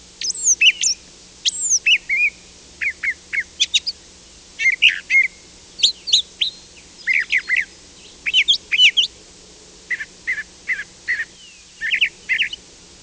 Sounds from the IBM Glen
Brown Thrasher.wav